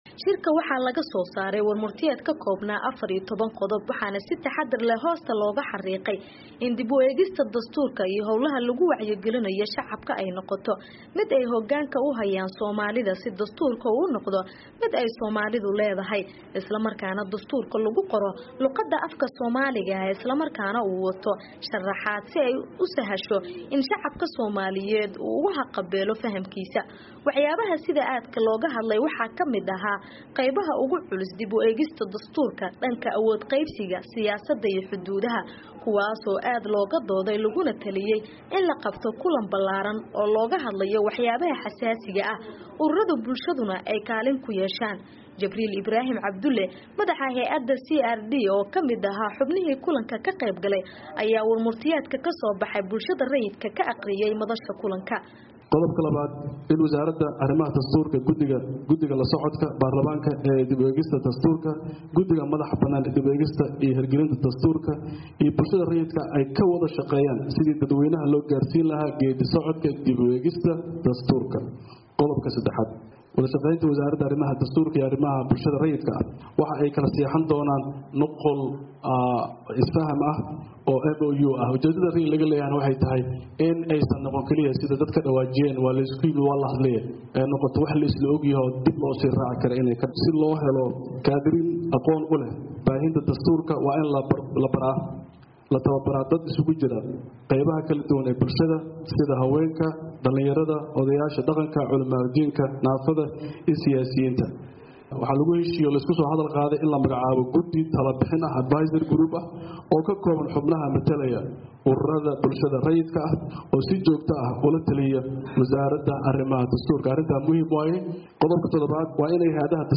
Warbixin: Shirka Dastuurka